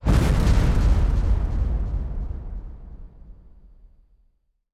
fx_crawlerexplosion_a.wav